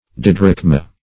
Search Result for " didrachma" : The Collaborative International Dictionary of English v.0.48: Didrachm \Di"drachm\, Didrachma \Di*drach"ma\, n. [Gr.